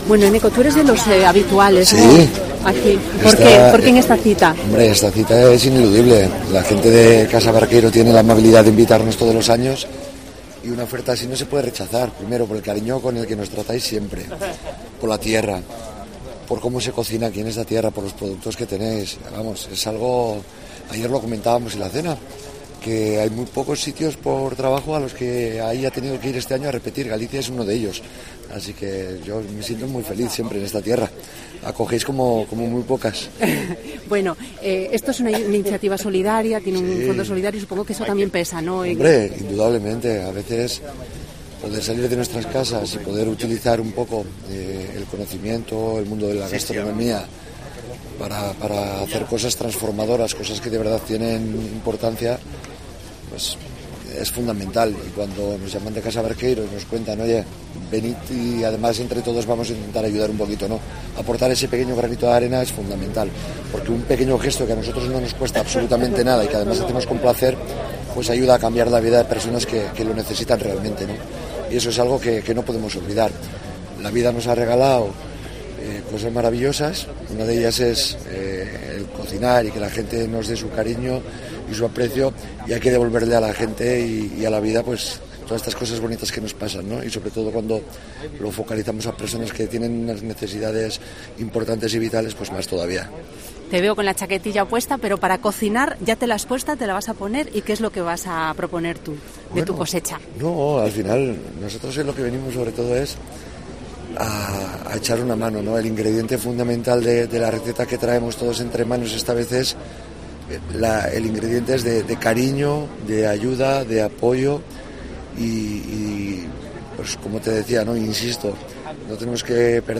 Entrevista con el